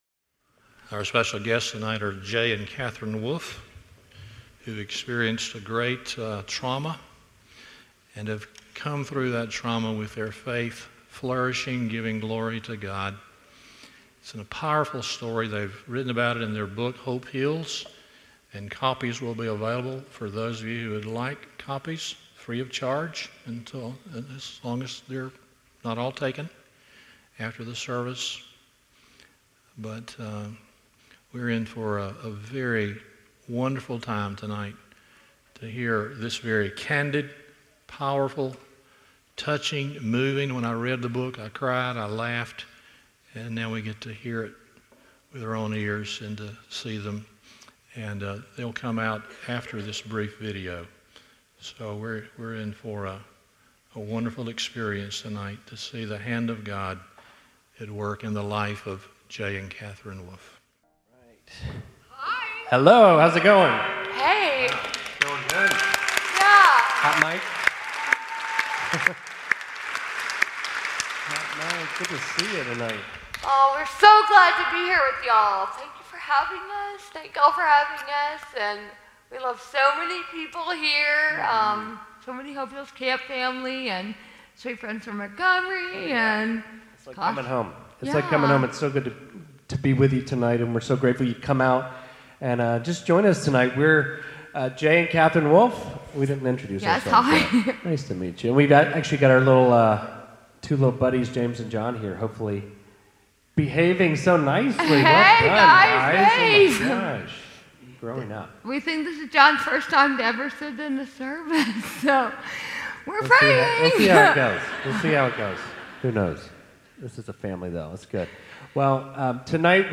Stand Alone Sermons Service Type: Sunday Evening